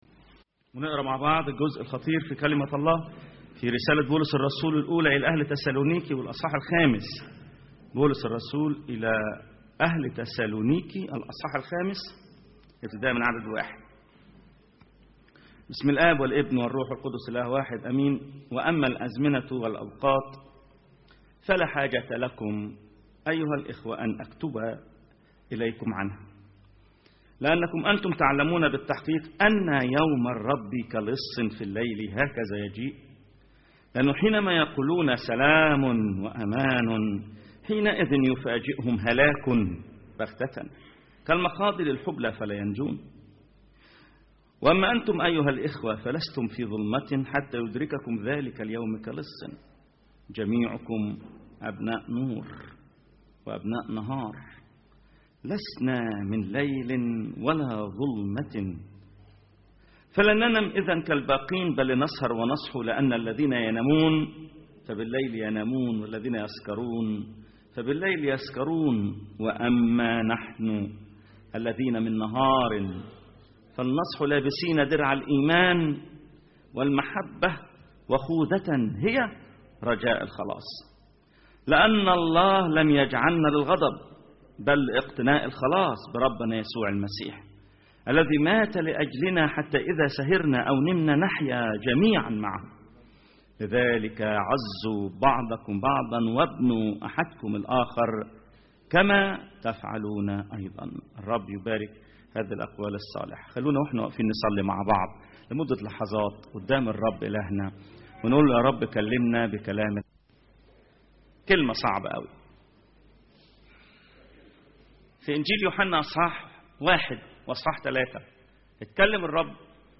ثلاث عظات من رسالة تسالونيكي الأولى،تدرس الخلاص من ثلاث جهات : الفرد – الأسرة – العالم .. العظة الثالثة – […]